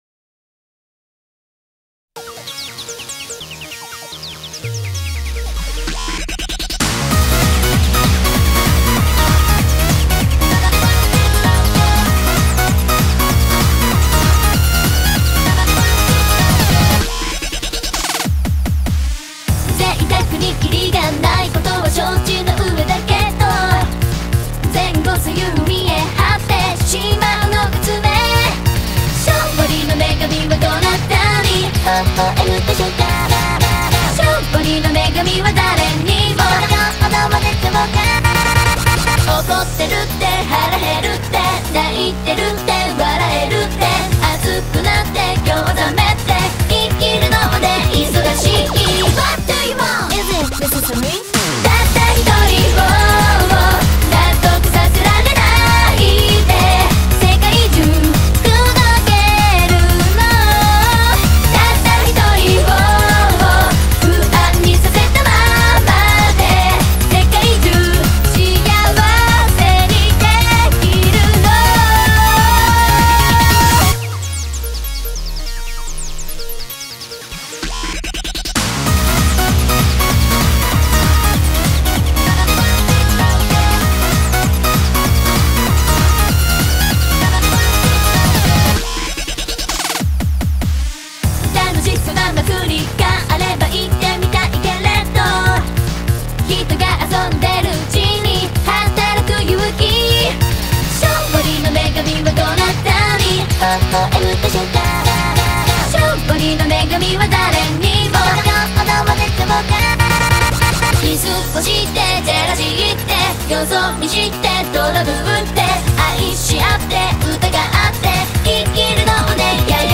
studio version